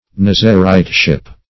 Nazariteship \Naz"a*rite*ship\, prop. n. The state of a Nazarite.